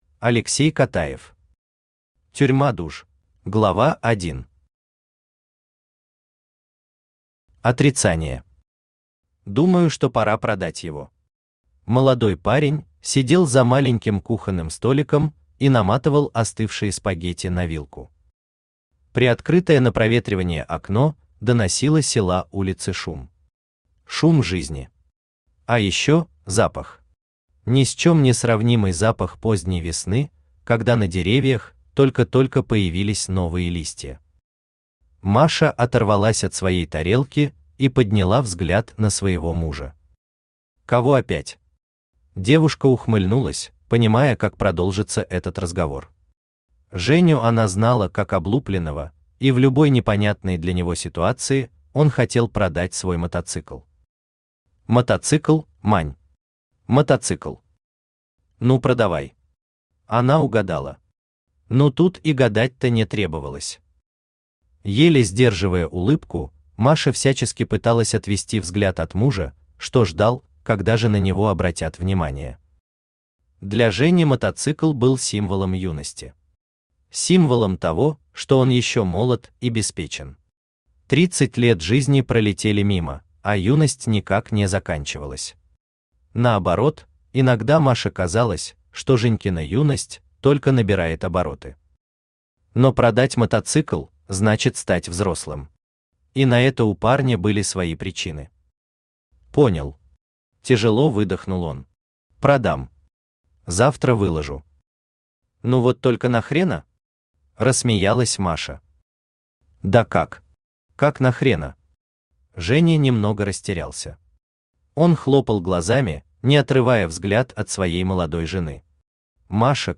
Аудиокнига Тюрьма душ | Библиотека аудиокниг
Aудиокнига Тюрьма душ Автор Алексей Котаев Читает аудиокнигу Авточтец ЛитРес.